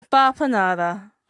talking.mp3